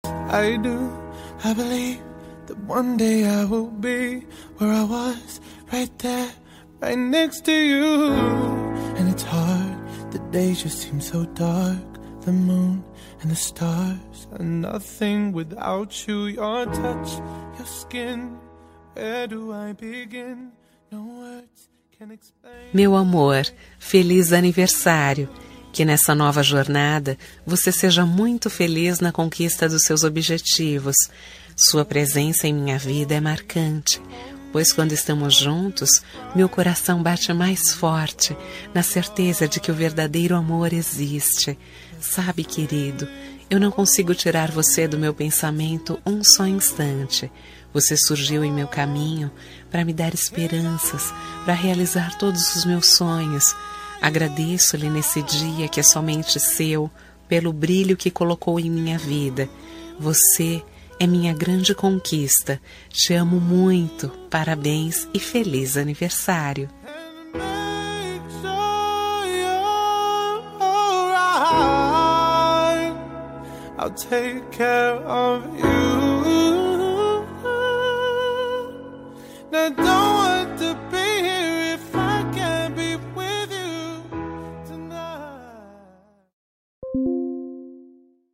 Aniversário Romântico – Voz Feminina – Cód: 350323